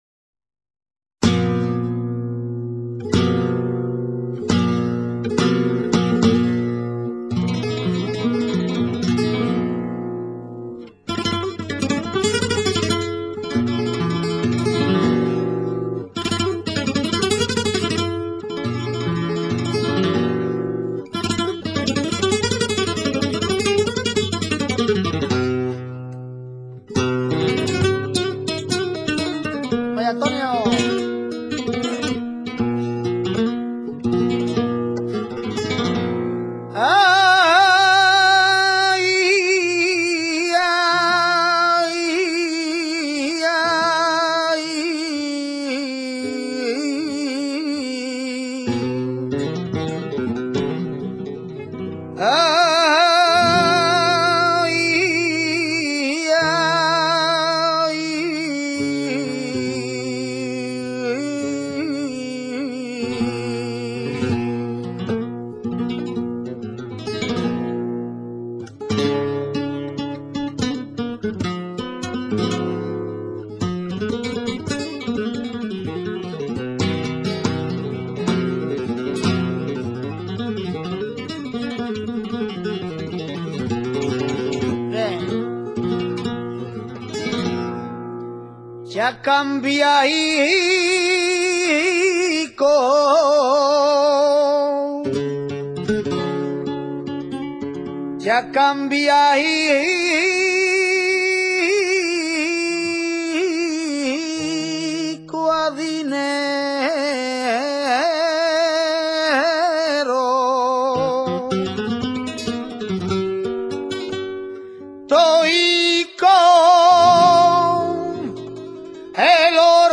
minera.mp3